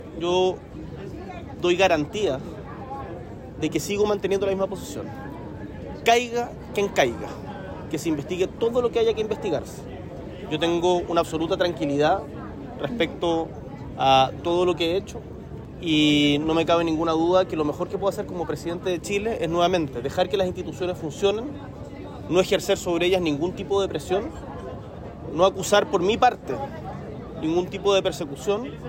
En medio de este contexto, desde Osaka, en el marco de su participación en la Expo Mundial 2025, el Presidente Boric aseguró estar tranquilo y reafirmó su compromiso con la institucionalidad.